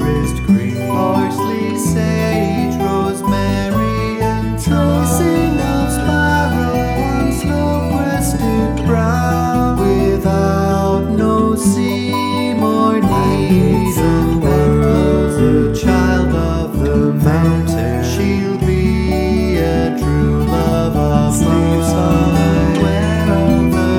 With Harmony Pop (1960s) 3:08 Buy £1.50